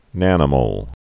(nănə-mōl)